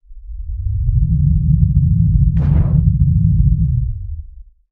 harvesterretract.ogg